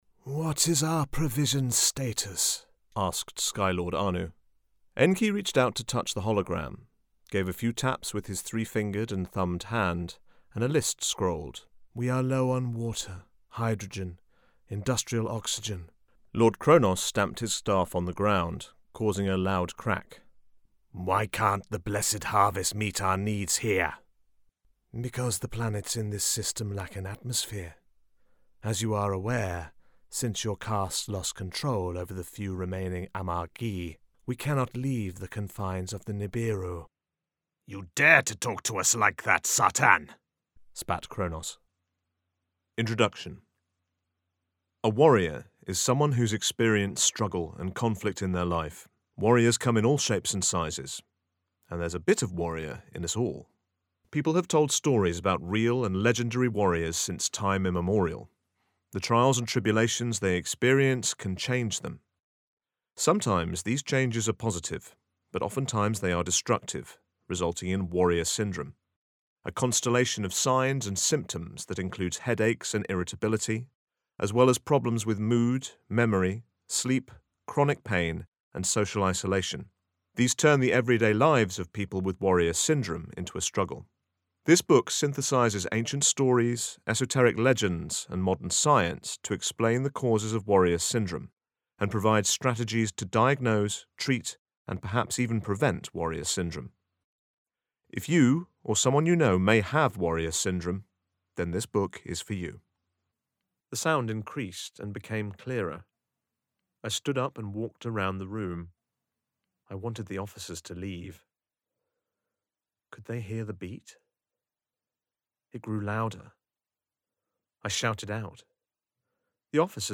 Male
Warm, convincing, trustworthy voice and genuine RP (Received Pronunciation) accent
Audiobooks
0320Audiobook_demo.mp3